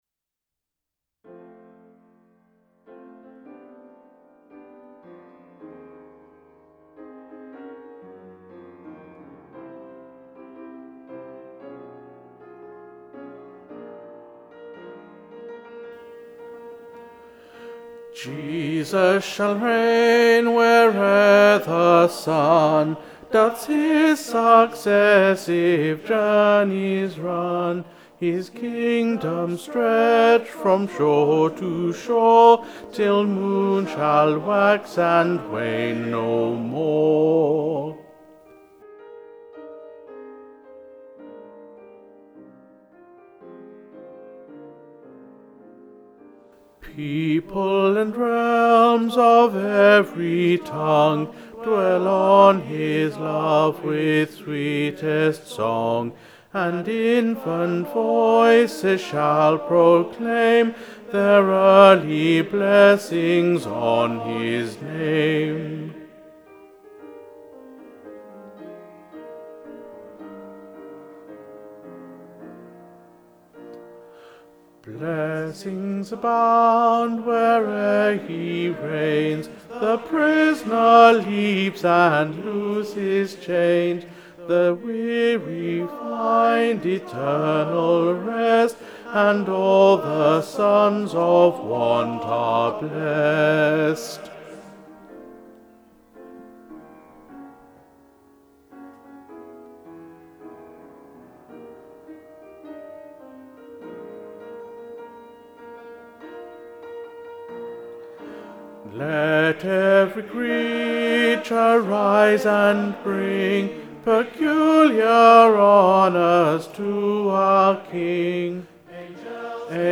Jesus-shall-reign-bass.mp3